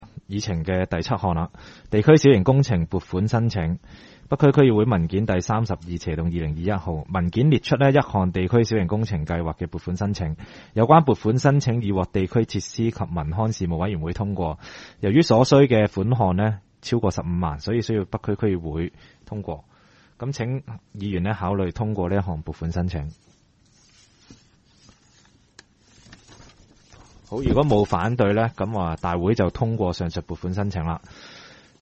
区议会大会的录音记录
北区区议会会议室